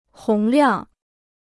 洪亮 (hóng liàng) Dictionnaire chinois gratuit